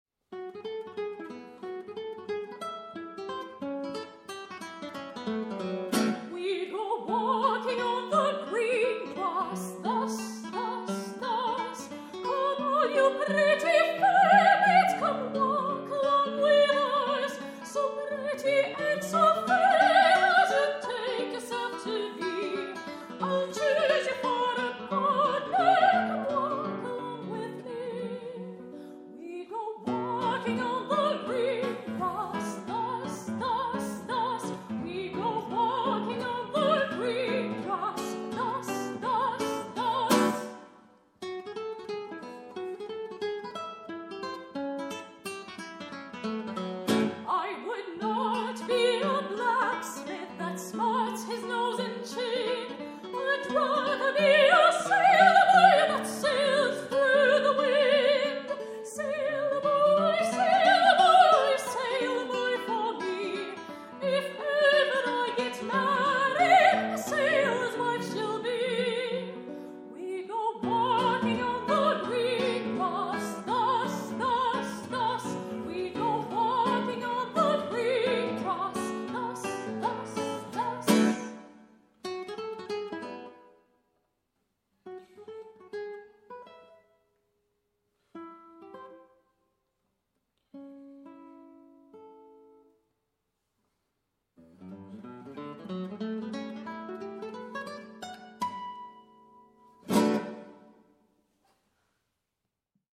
guitariste
soprano
Ils se consacrent à l’interprétations du répertoire classique et contemporain, ainsi qu’à la commande de nouvelles œuvres auprès de compositeurs et compositrices actuels, mettant en avant la complémentarité de la guitare et de la voix.